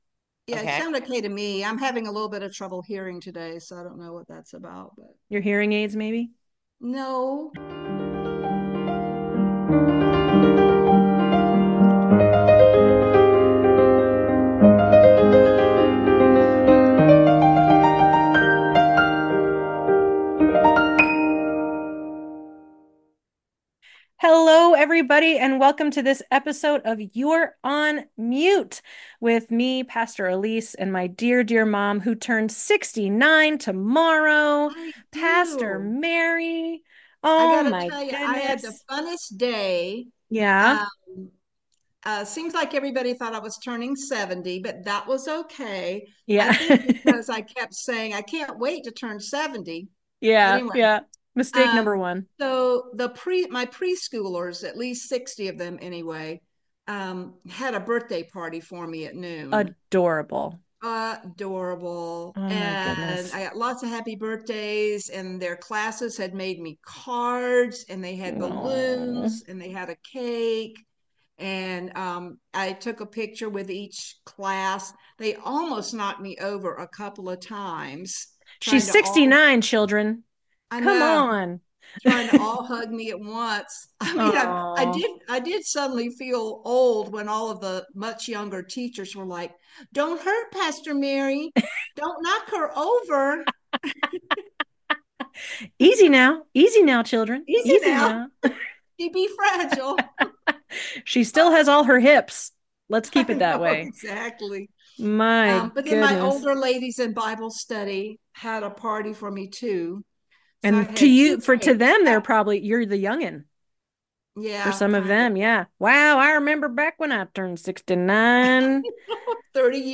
It is such a full and compelling conversation about the work of spiritual leadership, building community, interfaith partnerships, and diving into historical and current conflicts in the middle east that affect so many.